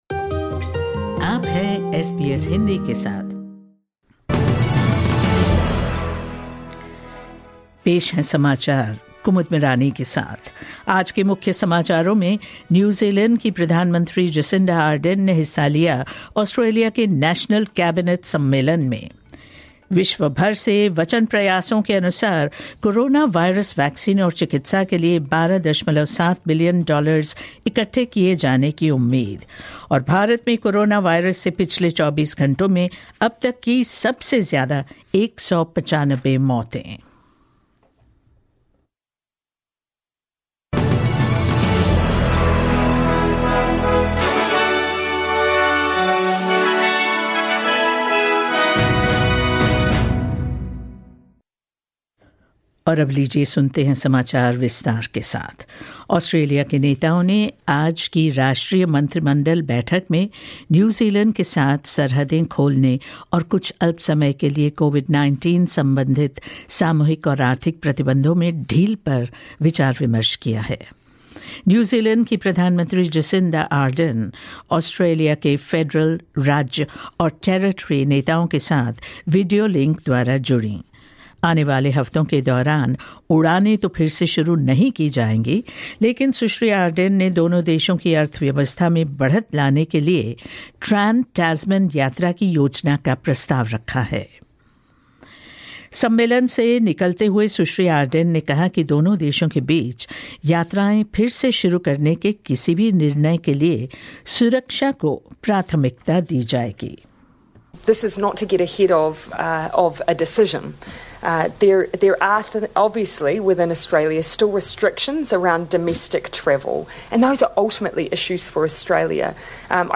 News in Hindi 05 May 2020